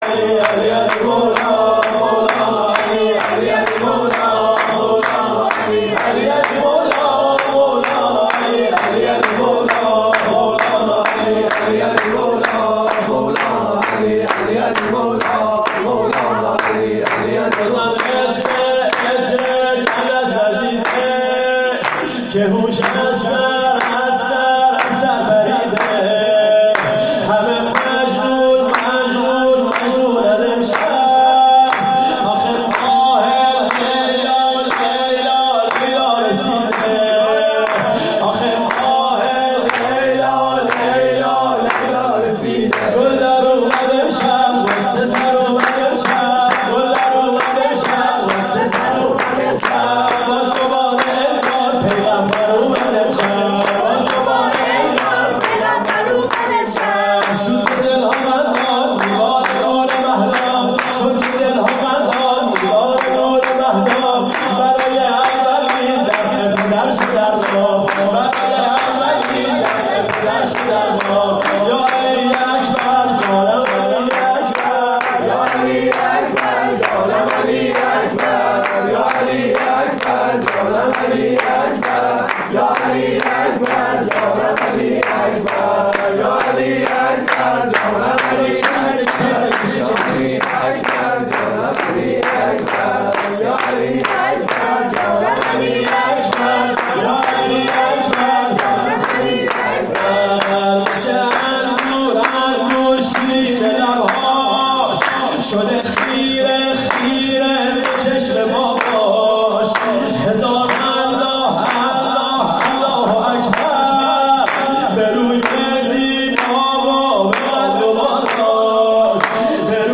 شب میلاد حضرت علی اکبر(ع)
سرود